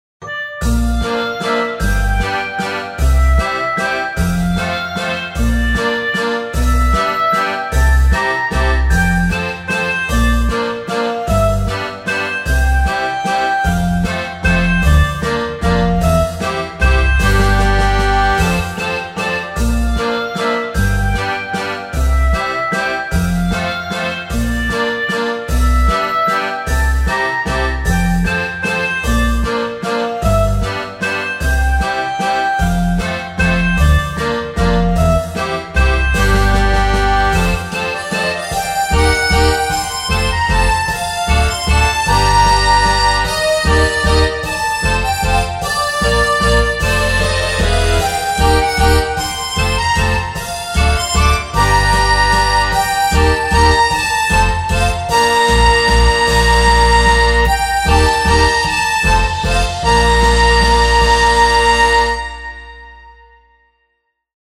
中世ヨーロッパ風の街並みやにぎやかな市場、石畳の路地を想像させるような明るさと懐かしさを兼ね備えた一曲となっています。
• 編成：クラリネット系の木管、ハープ、アコースティックベース、ベル系パッドなど
• テンポ：落ち着いた中速テンポ
• 調性：メジャー系で安心感を演出